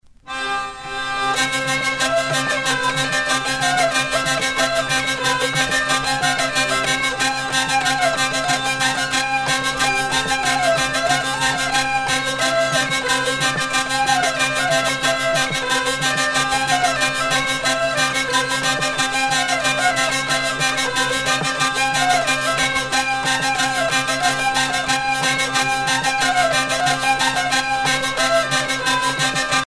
danse : rond
Sonneurs de vielle traditionnels